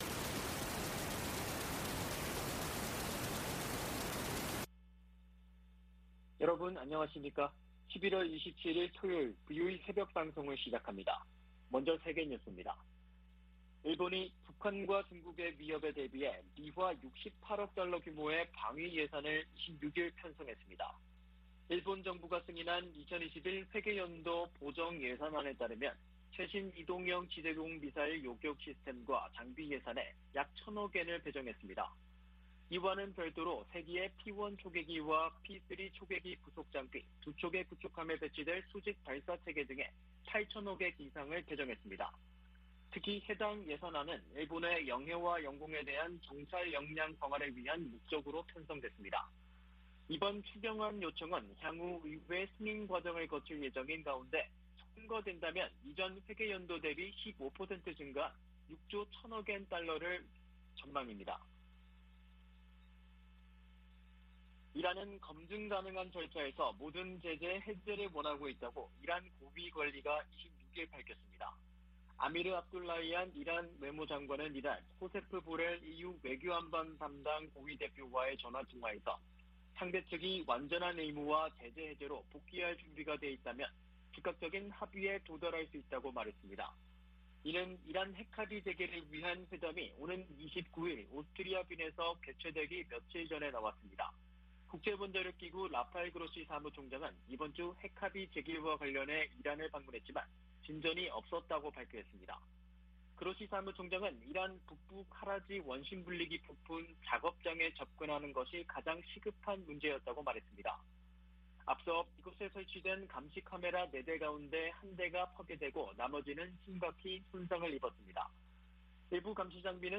VOA 한국어 '출발 뉴스 쇼', 2021년 11월 27일 방송입니다. 미 국무부는 북한의 계속된 핵 활동을 규탄하면서, 북한과의 비핵화 대화를 추구하고 있다고 밝혔습니다. 미국 상무부가 북한 유령회사에 미국과 다른 국가의 기술을 판매한 중국 기업 등, 국가안보에 위협이 되는 해외 기업들을 수출 규제 대상으로 지정했습니다. 북한 국영 고려항공이 또다시 유럽연합 회원국 내 운항이 엄격히 제한되는 항공사로 지정됐습니다.